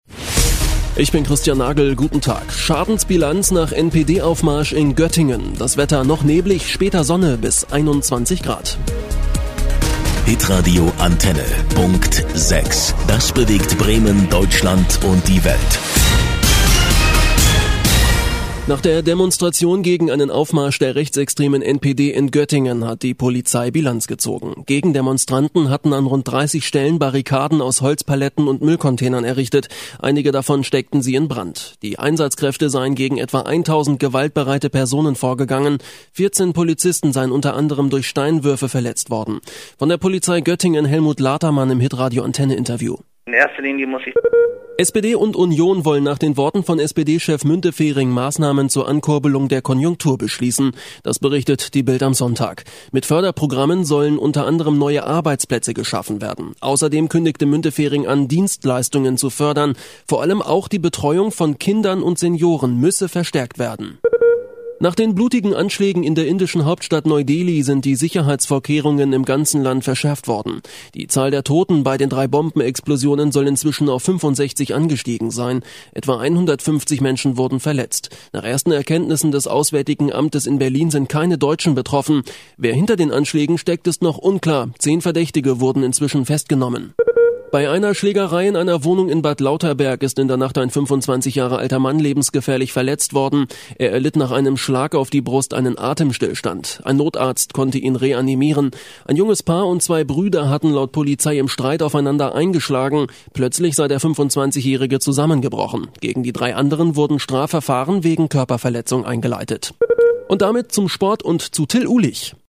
deutscher Sprecher.
norddeutsch
Sprechprobe: eLearning (Muttersprache):